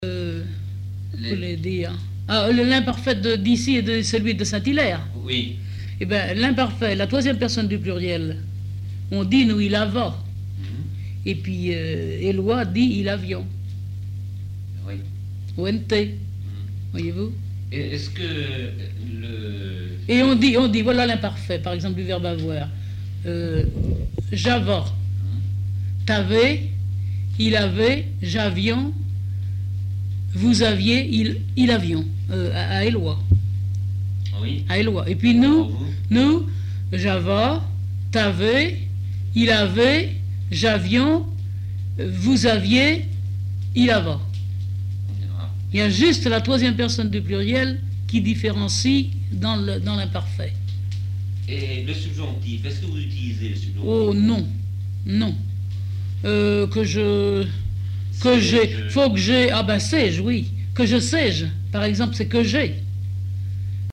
Chauvé
textes en patois et explications sur la prononciation
Catégorie Témoignage